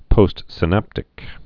(pōstsĭ-năptĭk)